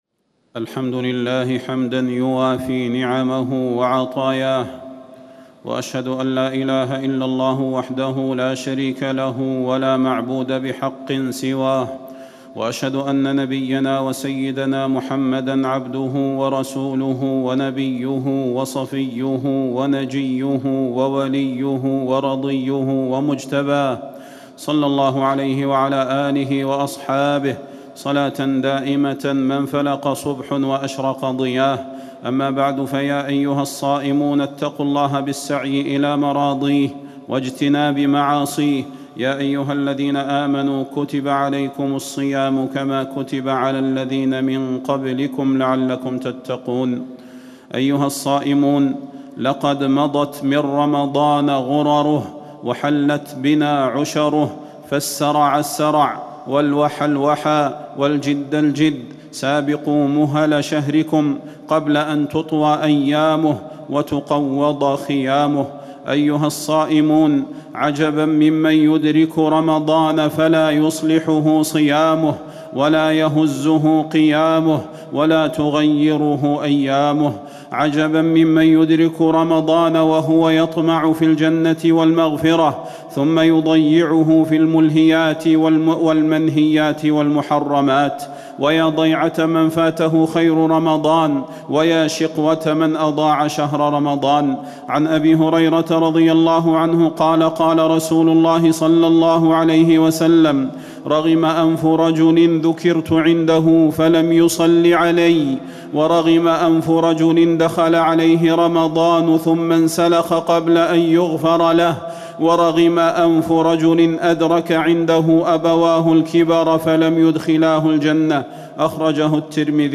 تاريخ النشر ٩ رمضان ١٤٣٩ هـ المكان: المسجد النبوي الشيخ: فضيلة الشيخ د. صلاح بن محمد البدير فضيلة الشيخ د. صلاح بن محمد البدير صوم المتقين